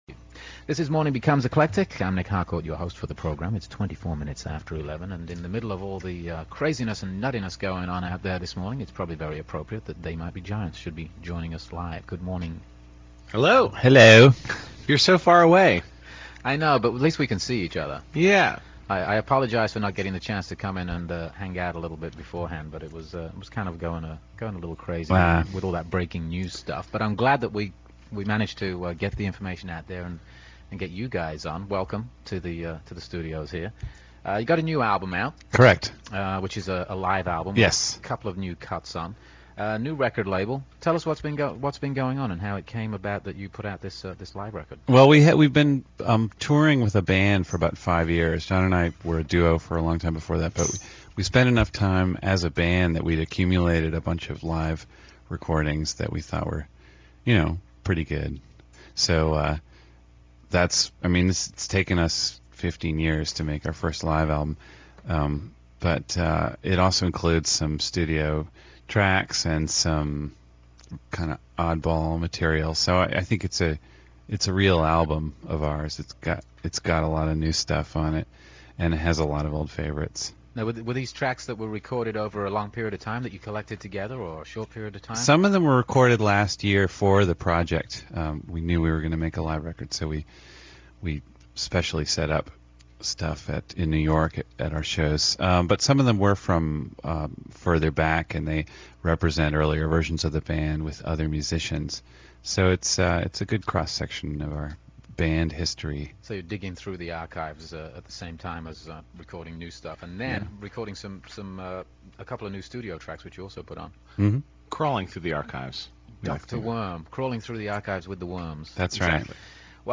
Download the complete show, with interviews